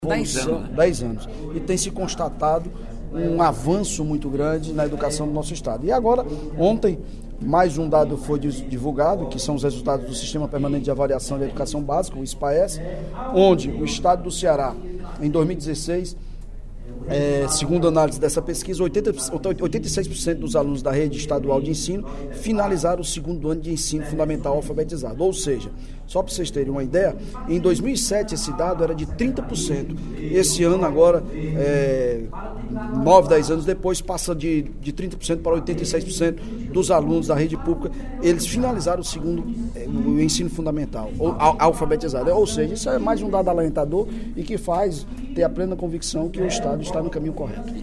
O líder do Governo na Casa, deputado Evandro Leitão (PDT), comemorou, durante o primeiro expediente da sessão plenária desta quarta-feira (15/02) os resultados do Sistema Permanente de Avaliação da Educação Básica de 2016 (Spaece). Segundo o parlamentar, os níveis de alfabetização estão crescendo no sistema público de ensino.